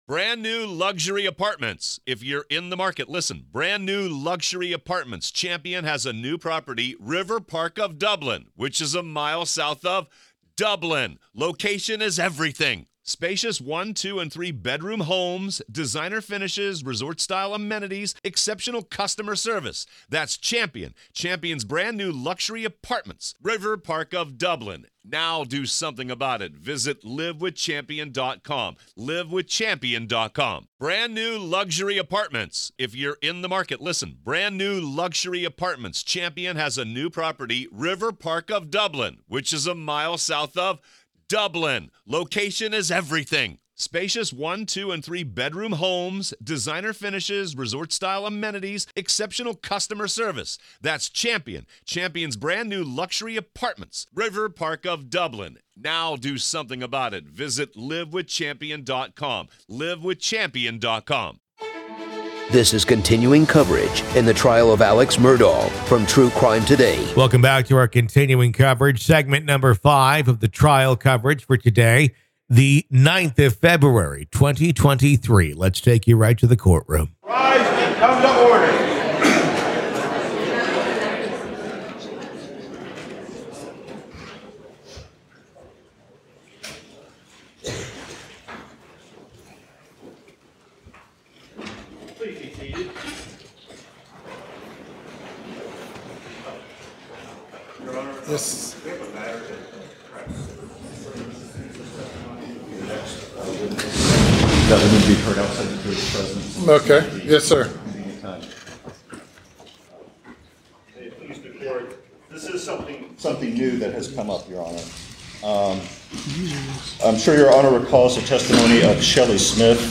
The Trial Of Alex Murdaugh | FULL TRIAL COVERAGE Day 12 - Part 5